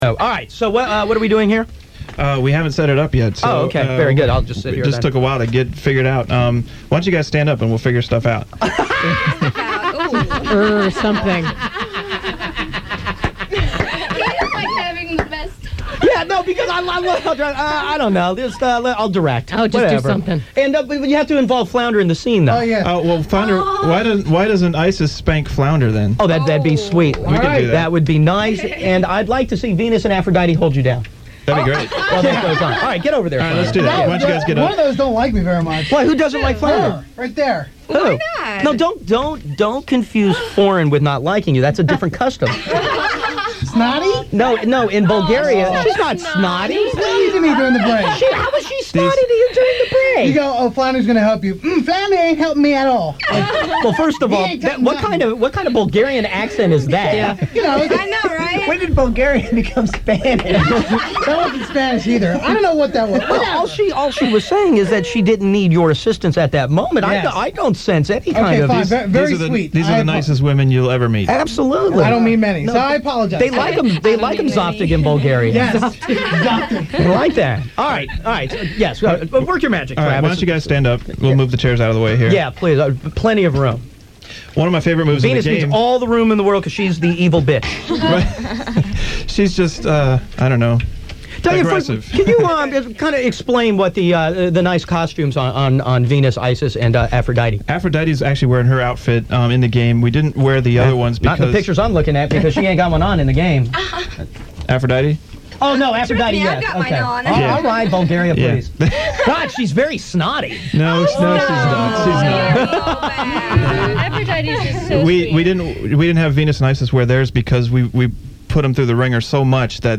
appeared at a local radio station to talk about being in Bikini Karate Babes